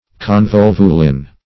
Search Result for " convolvulin" : The Collaborative International Dictionary of English v.0.48: Convolvulin \Con*vol"vu*lin\, n. (Chem.)
convolvulin.mp3